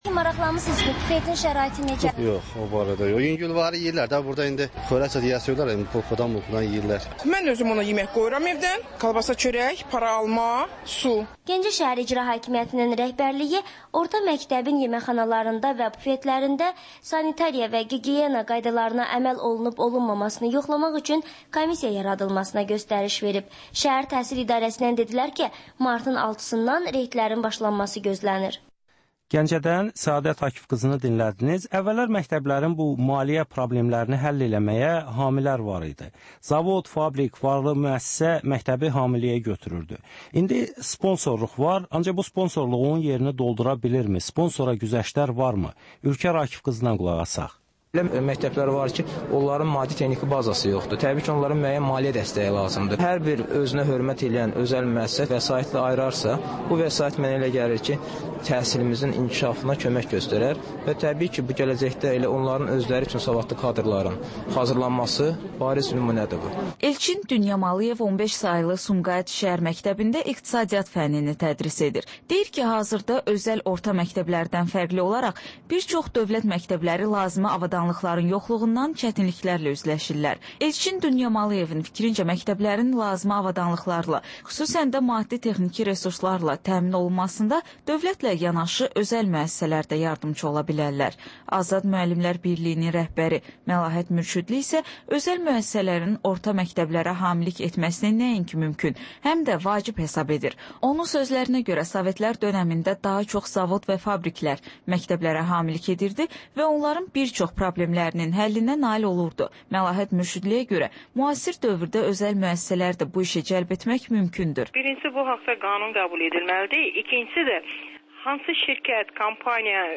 Azərbaycan Şəkilləri: Rayonlardan reportajlar.